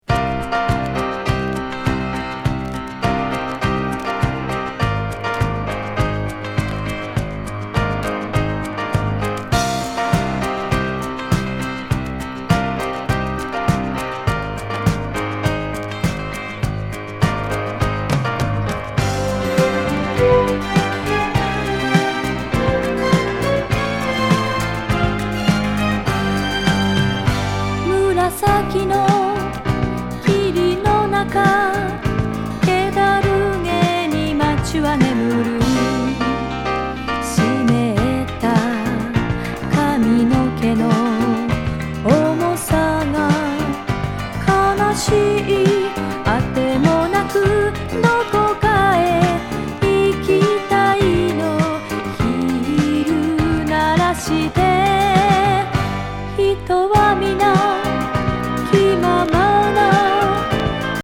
エスノ・グルーヴィ歌謡